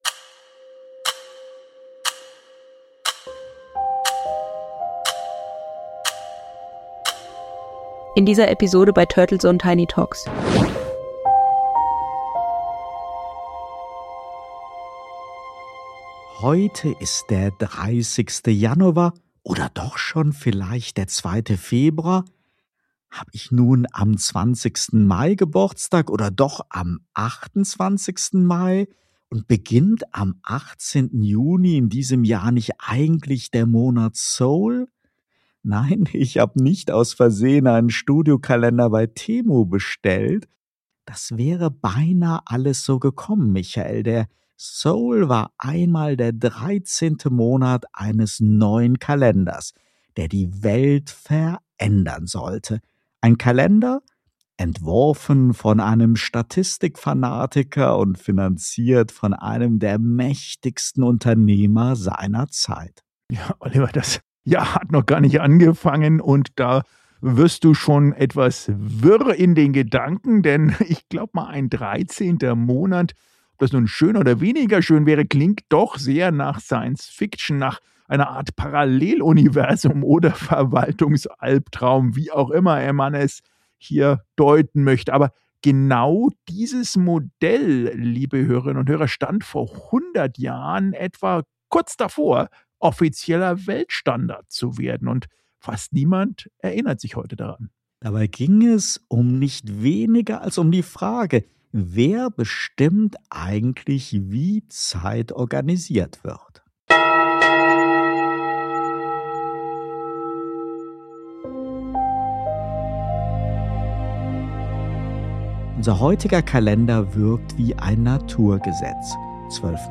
Der Debatten-Podcast